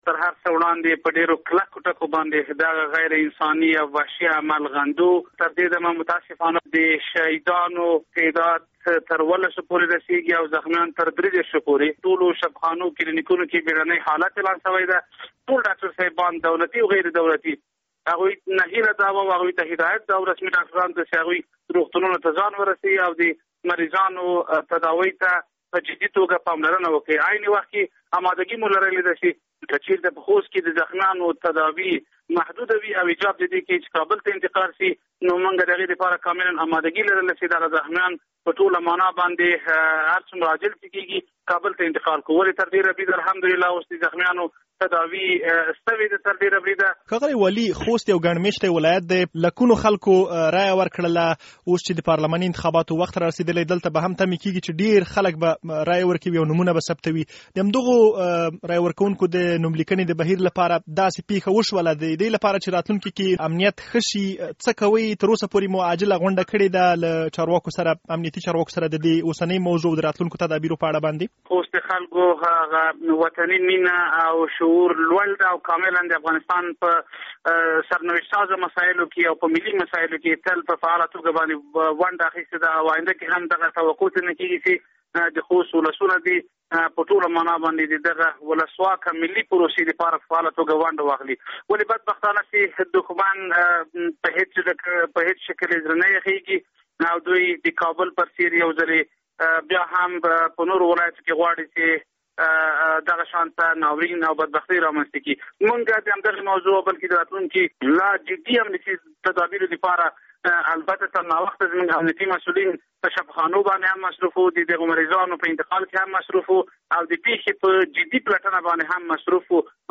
له ښاغلي حبیبي سره مرکه